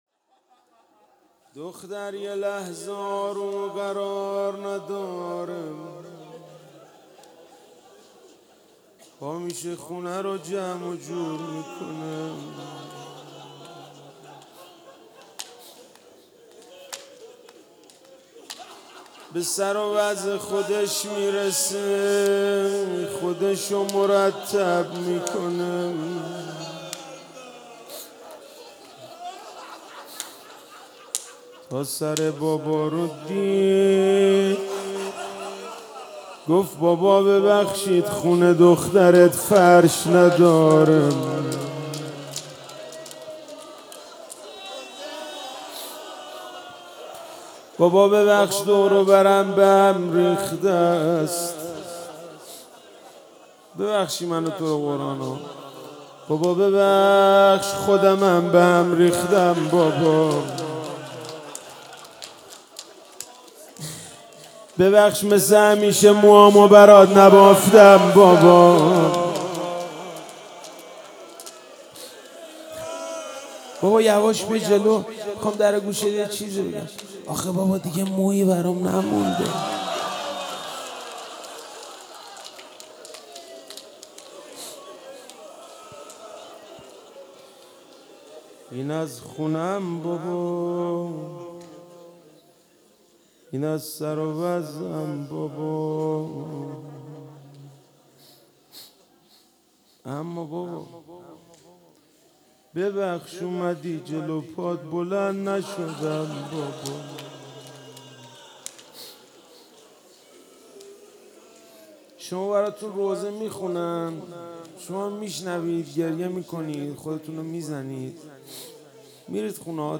شهادت حضرت رقیه1400 - روضه